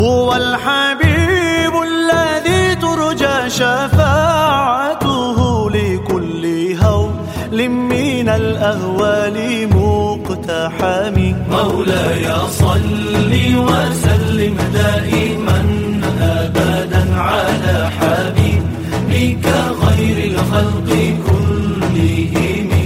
Spiritual melody nourishes the soul